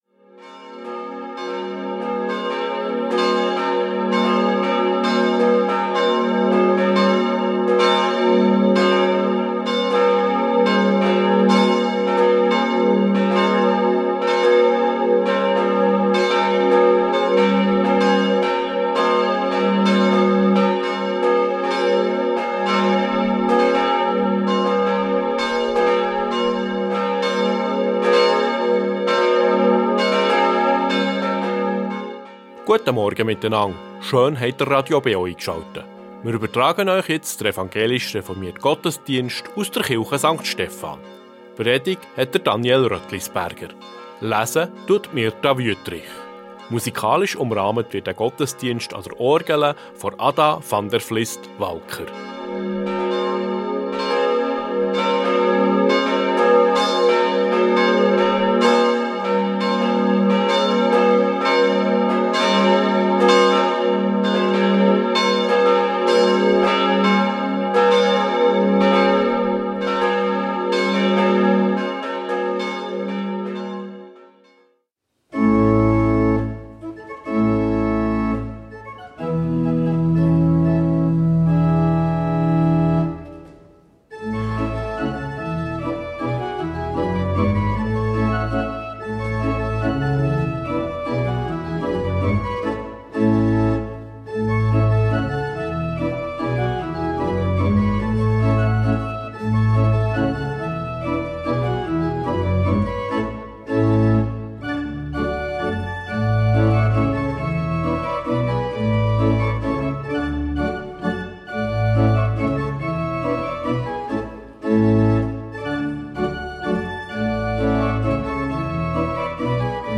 Reformierte Kirche St. Stephan ~ Gottesdienst auf Radio BeO Podcast